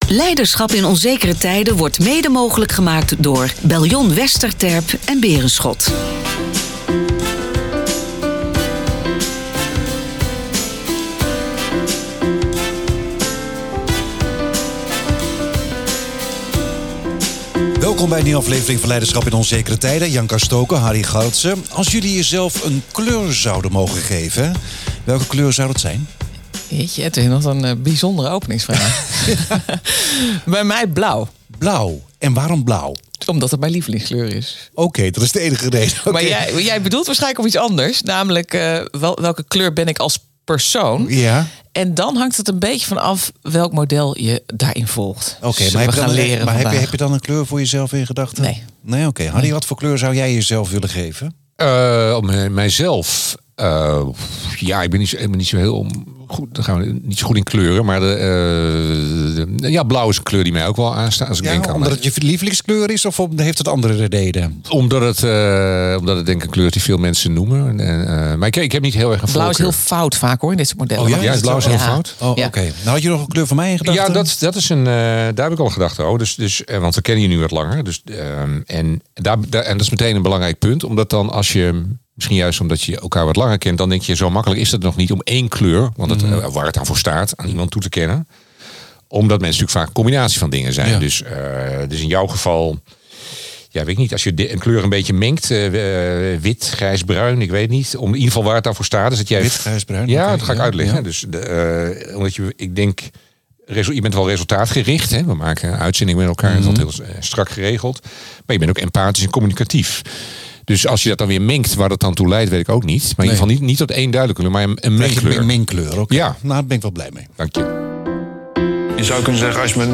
Wanneer ben je productiever, thuis of op het werk?Deze aflevering werd met publiek opgenomen op het Noorderlink podium op het Grootste Kennisfestival in Groningen.